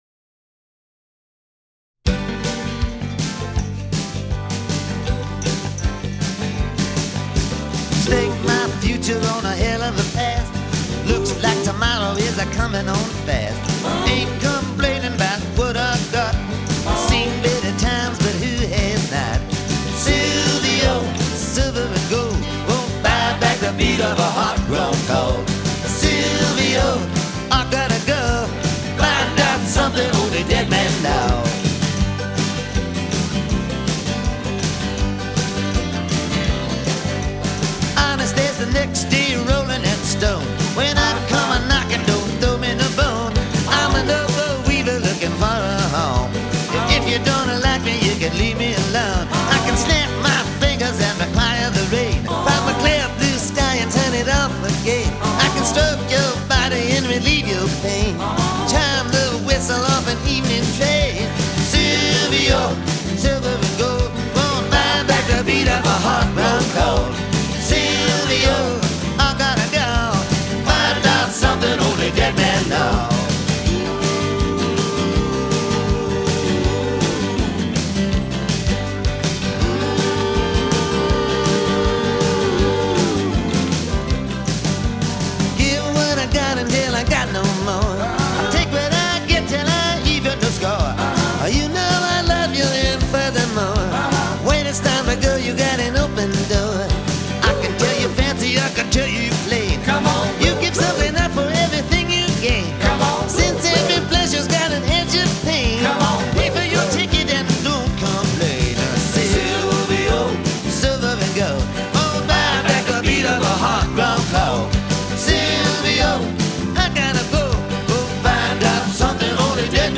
a jaunty track
is a fun song that rocks.